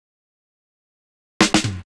Fill 128 BPM (19).wav